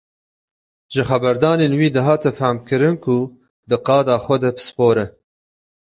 /pɪsˈpoːɾ/